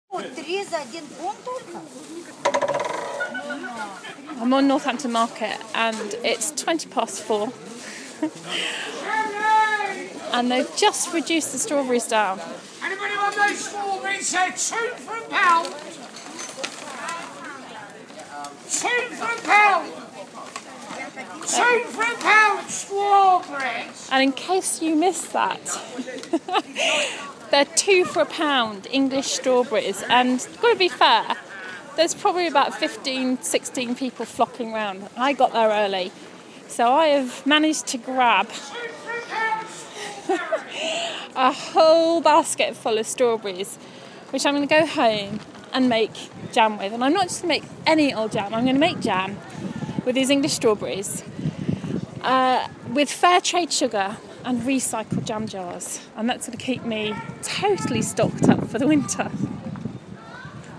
Buying strawberries on the market at the end of the day to make jam